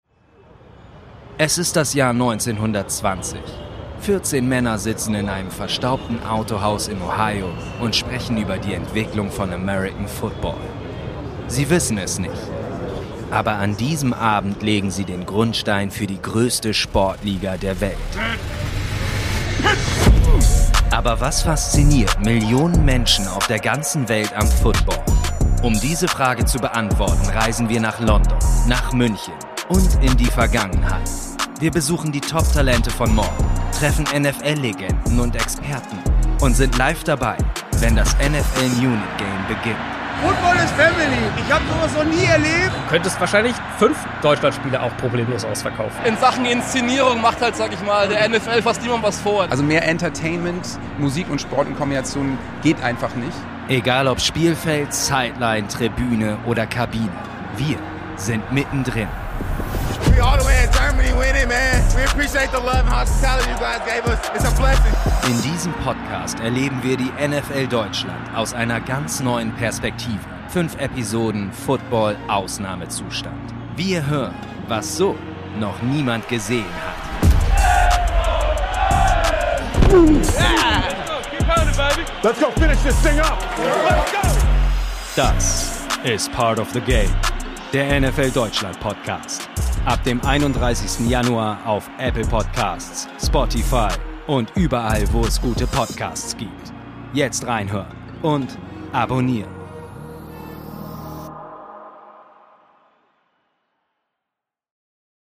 In dieser Audio-Dokumentation hören wir, was vorher noch niemand gesehen hat.
• Interviews mit NFL-Legenden, Experten, Promis und Fans